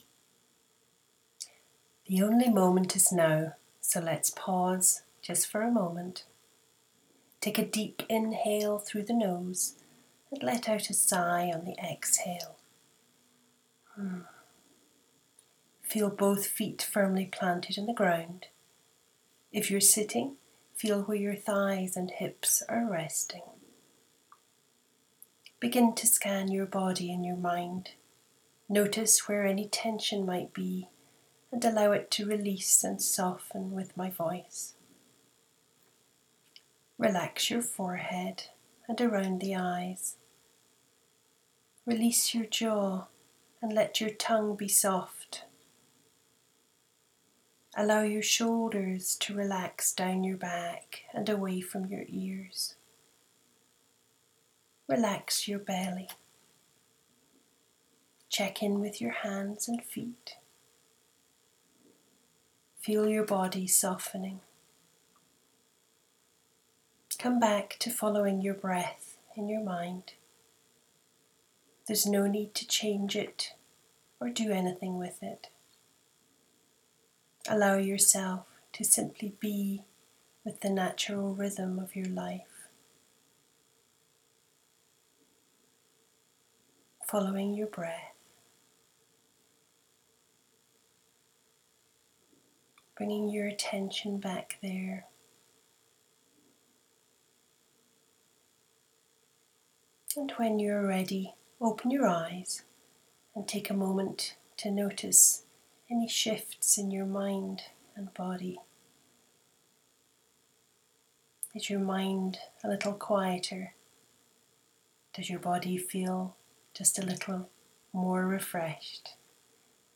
meditation
Here is a 2 minute meditation in my own voice.
You have the PERFECT voice for leading meditations.
Its that Scottish lilt coming through ….
Well, there is a sort of high hiss in the background you might want to check.